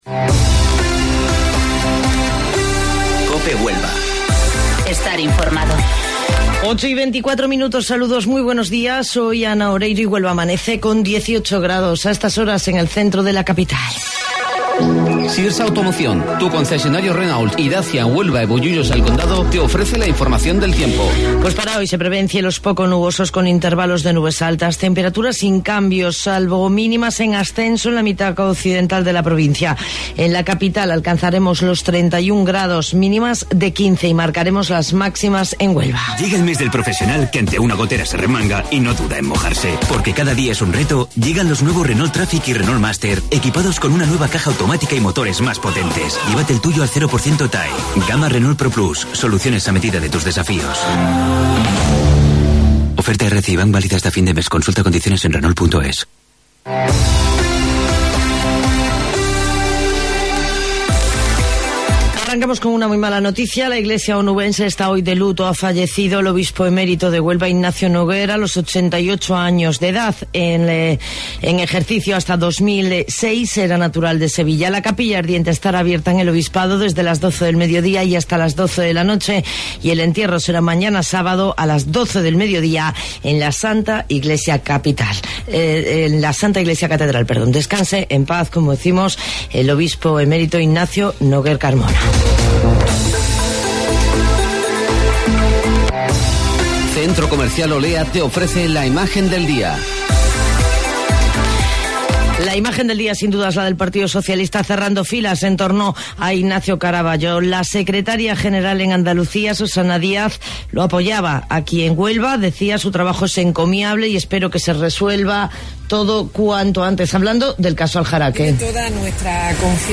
AUDIO: Informativo Local 08:25 del 4 de Octubre